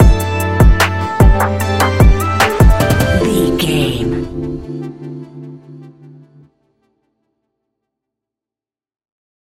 Ionian/Major
D
laid back
Lounge
sparse
new age
chilled electronica
ambient
atmospheric